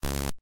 snd_error.ogg